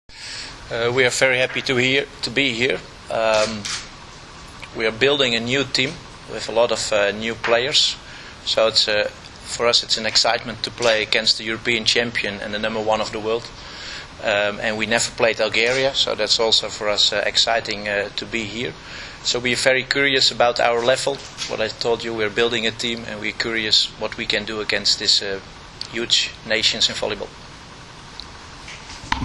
U beogradskom hotelu “M” danas je održana konferencija za novinare povodom predstojećeg turnira F grupe II vikenda XXI Gran Prija 2013.
IZJAVA